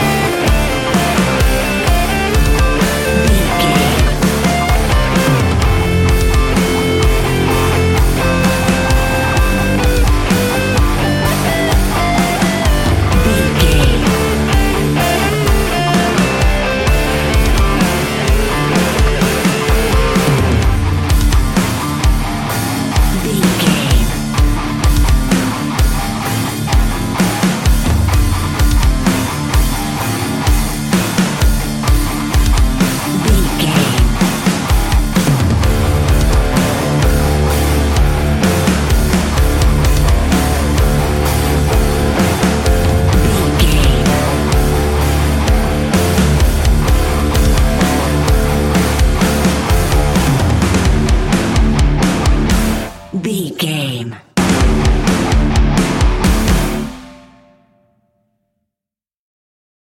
Ionian/Major
D
heavy rock
heavy metal
instrumentals